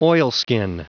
Prononciation du mot oilskin en anglais (fichier audio)
Prononciation du mot : oilskin